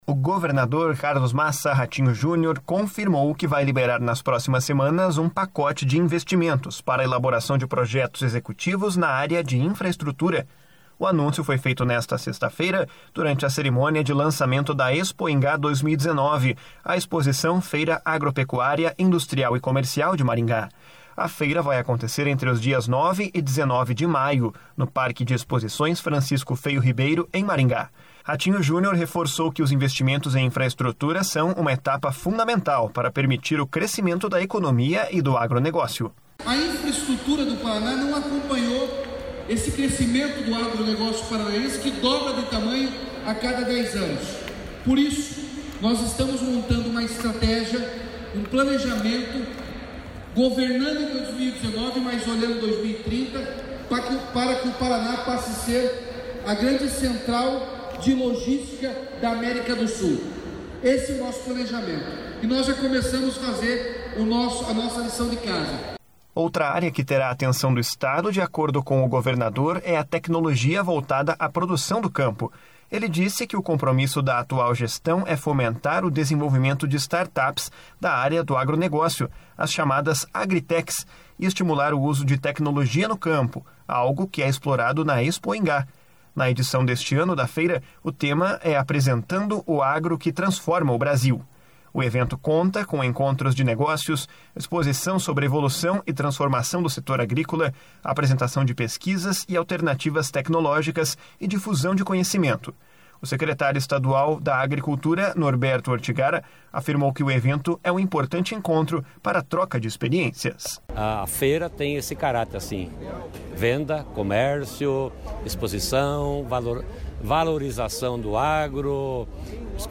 // SONORA RATINHO JUNIOR //
O secretário estadual da Agricultura, Norberto Ortigara, afirmou que o evento é um importante encontro para troca de experiências. // SONORA NORBERTO ORTIGARA //